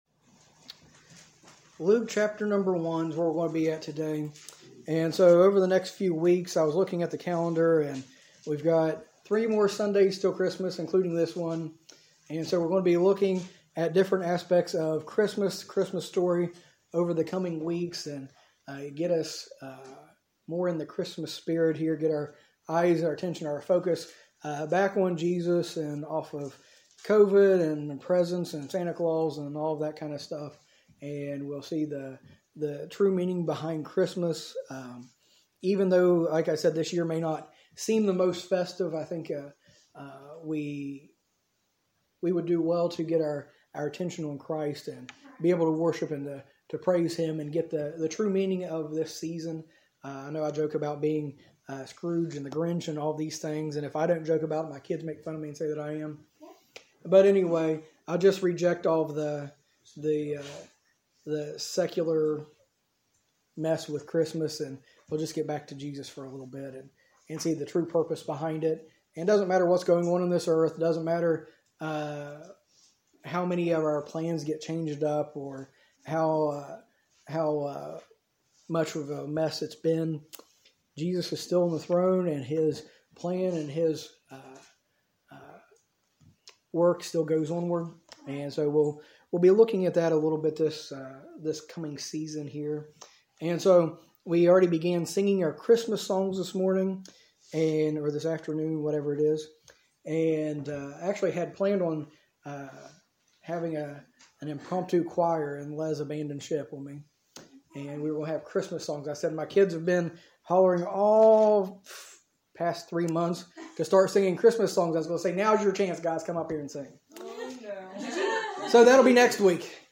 A message from the series "Series Breaks."